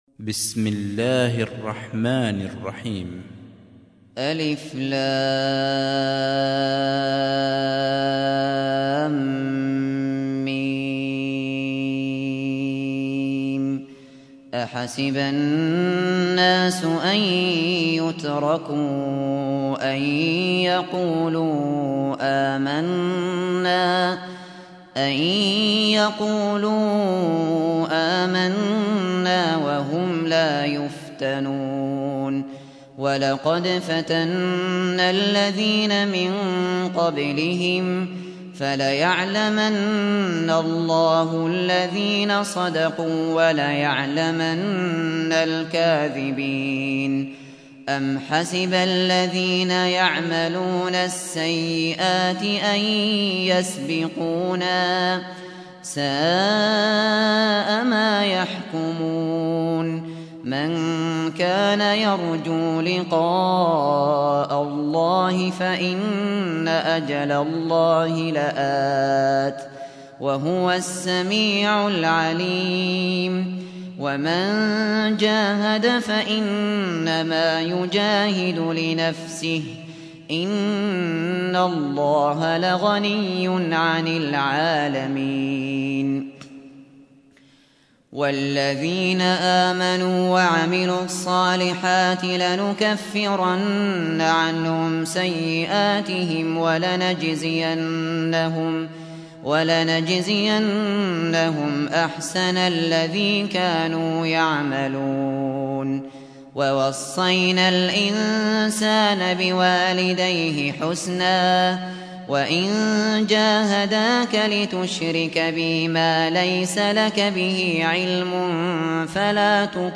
سُورَةُ العَنكَبُوتِ بصوت الشيخ ابو بكر الشاطري